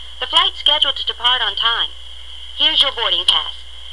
(加連線者為連音，加網底者不需唸出聲或音很弱。)